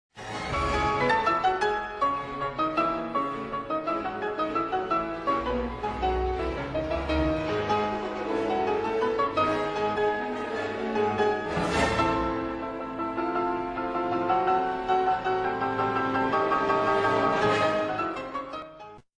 Klavierkonzert in einem Satz